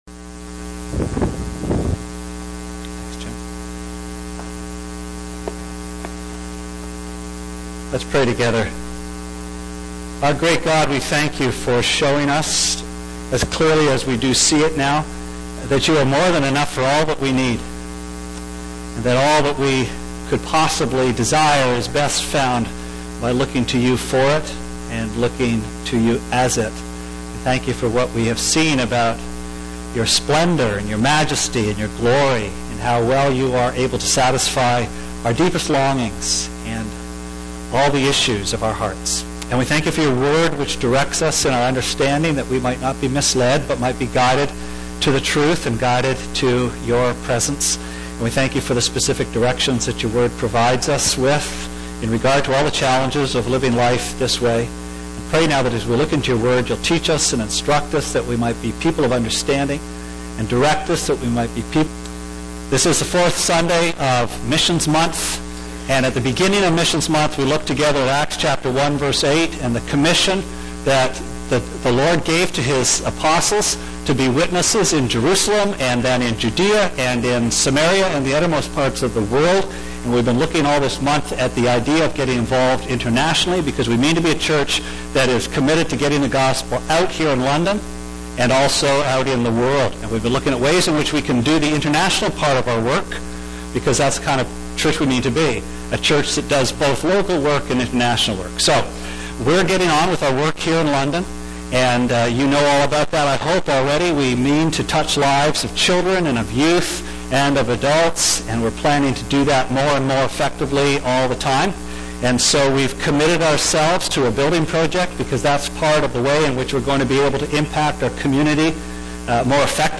Sermon Archives Nov 25